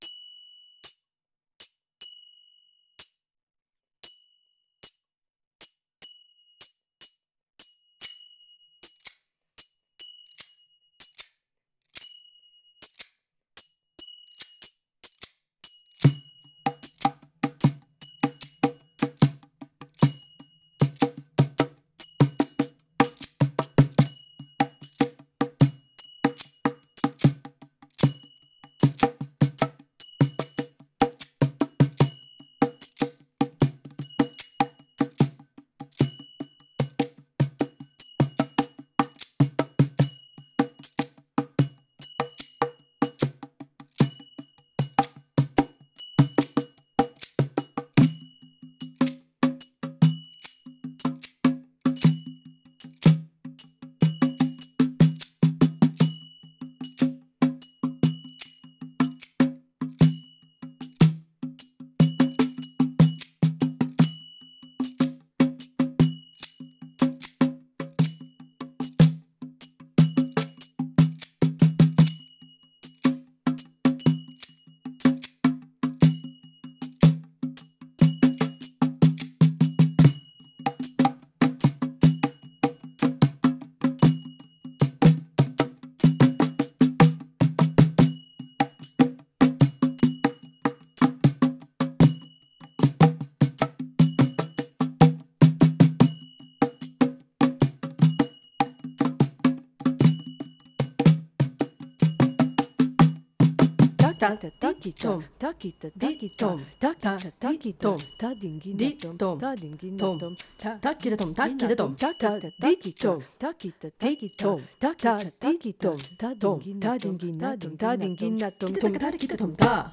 Searching for a Musical Middle Way: A Composition for Multi-traditional Percussion